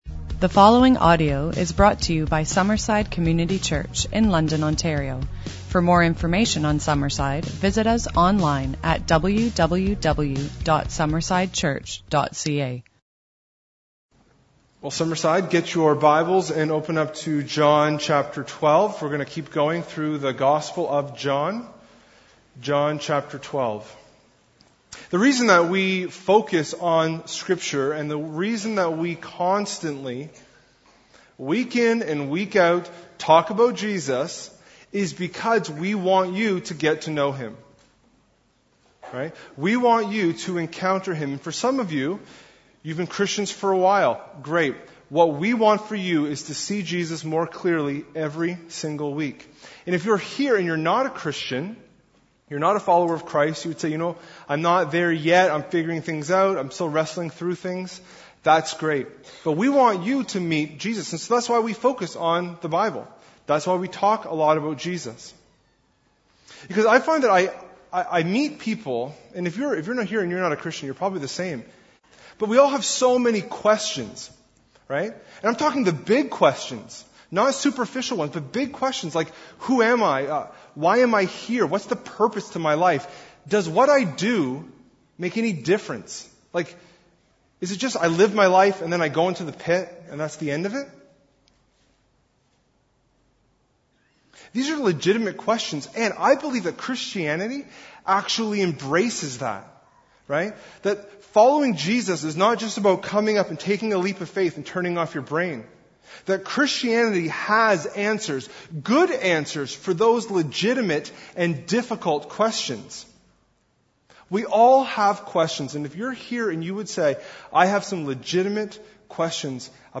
2016 Sermons